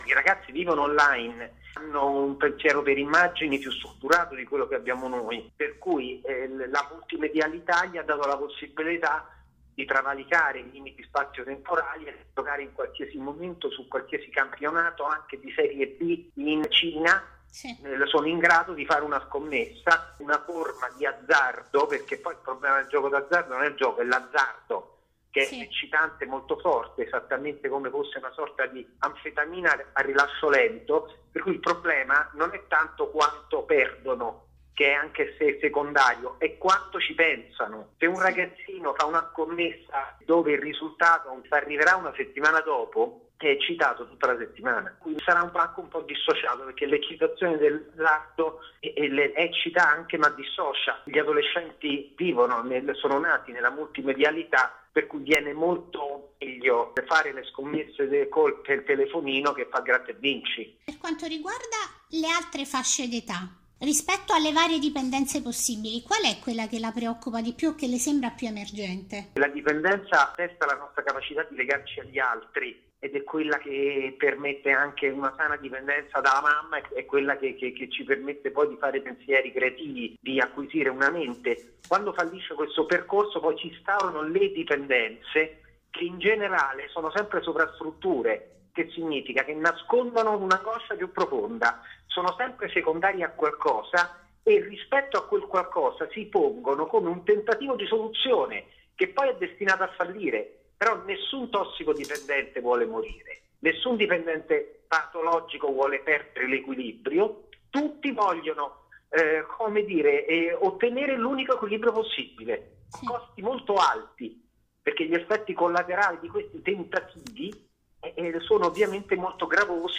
Italia > Audioletture